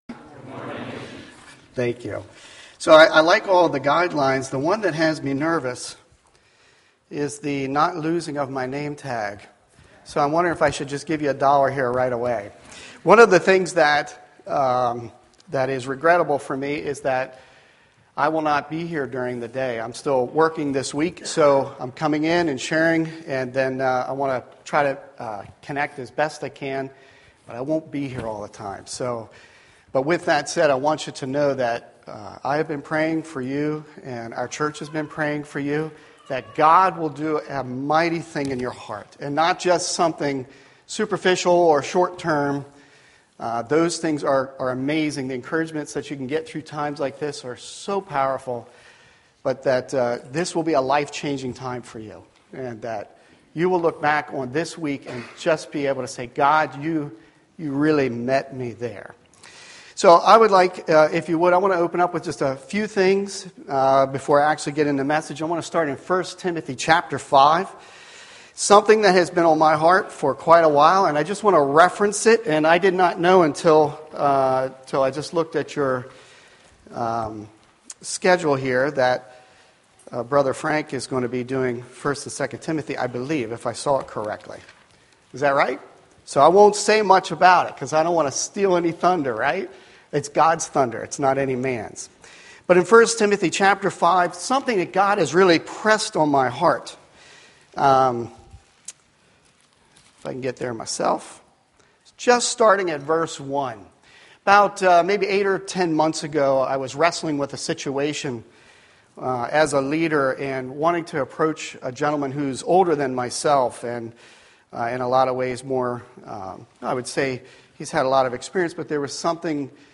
Series: Being Jesus' Disciple In A Believing Culture, Youth Bible School 2019